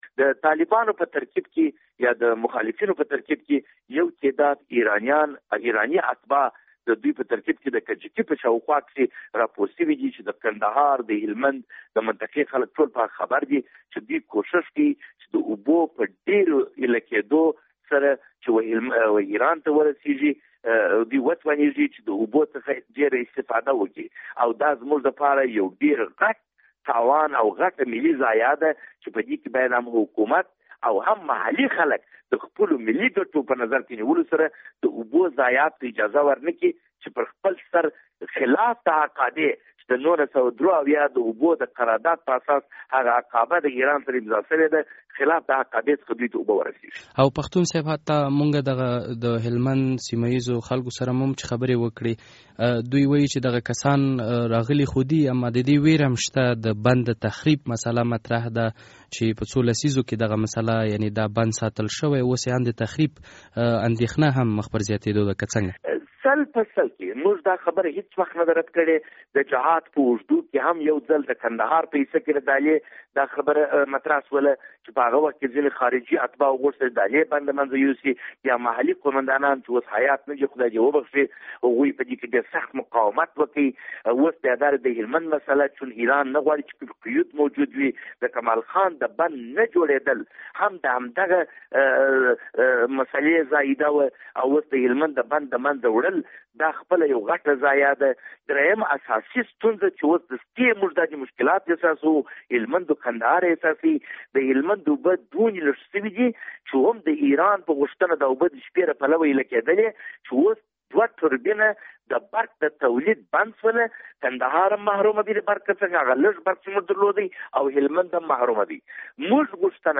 له خالد پښتون سره مرکه